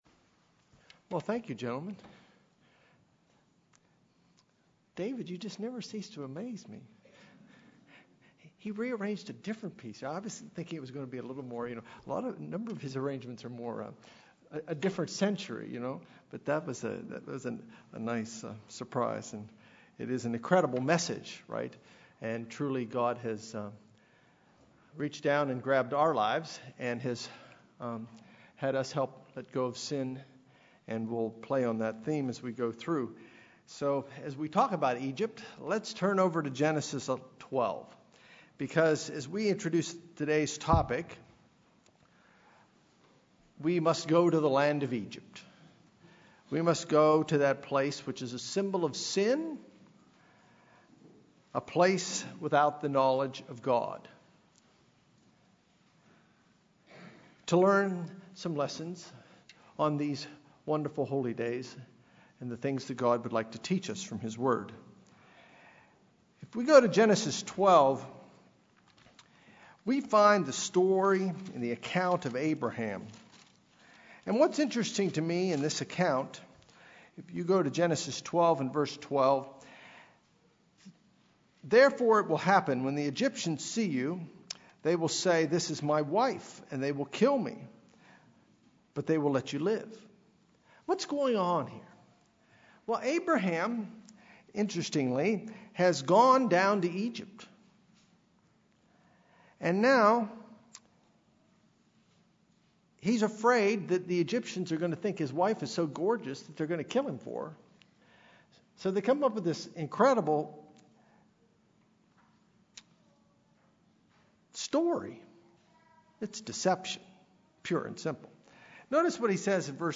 Given during the Days of Unleavened Bread, this message examines what it means to "deny" ourselves and what it means to "deny" God and His Son, Jesus Christ. It is important to deny our natural tendencies toward sin and look for ways in which we might be engaging in a denial of God.
Sermons